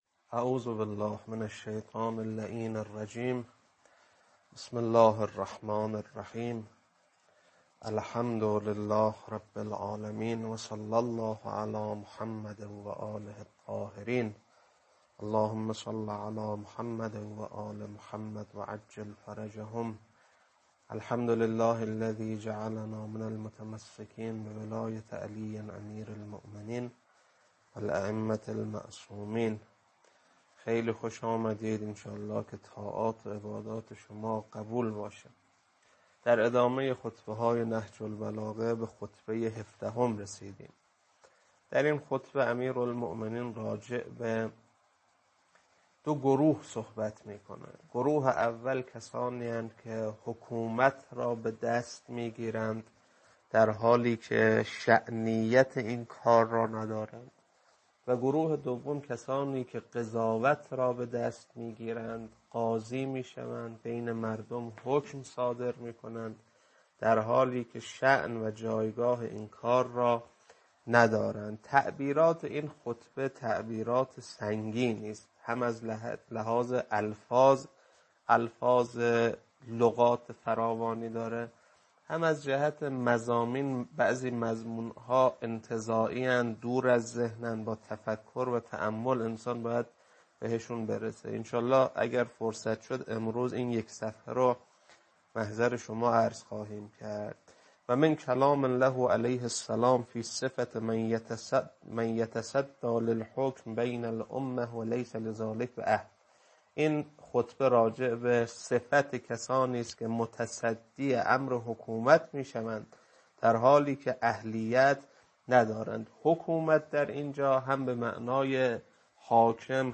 خطبه 17.mp3